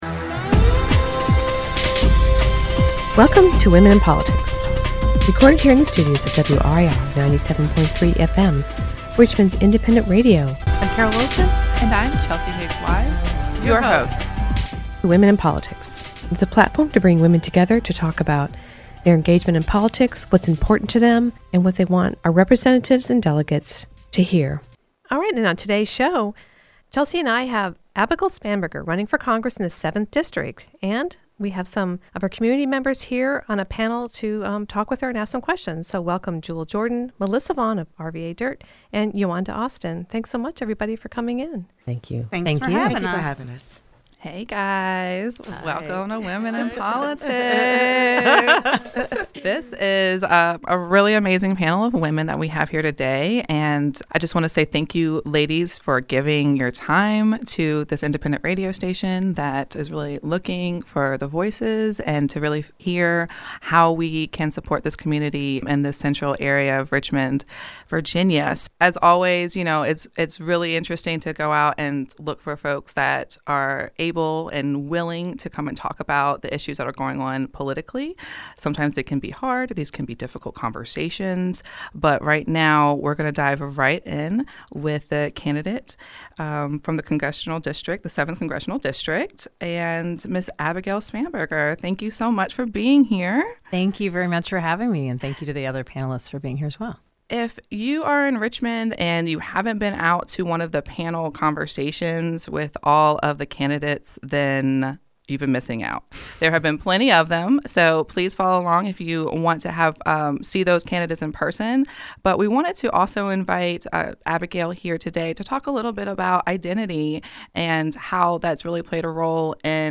Women and Politics: Abigail Spanberger joins community panel – Part 1
into the studios today